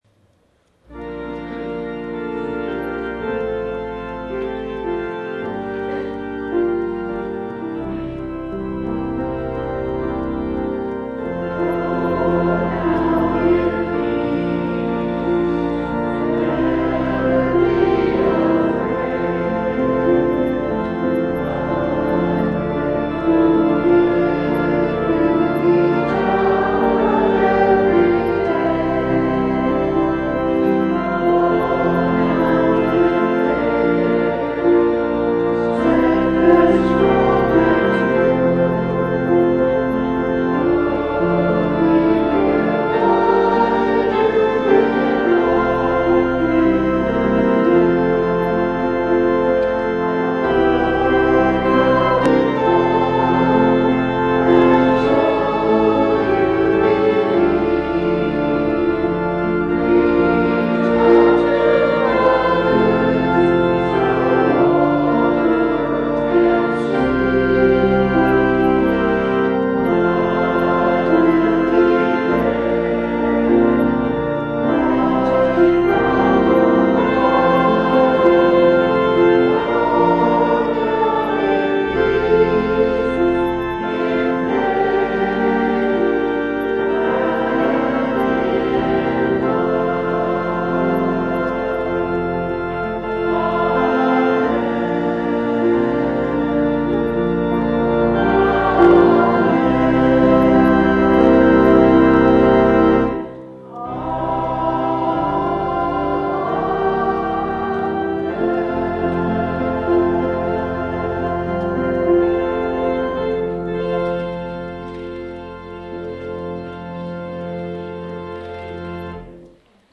Download   Benediction // Choir & People: “Go Now in Peace” – Price & Besig. This is our traditional Benediction, as sung on 4/3/2016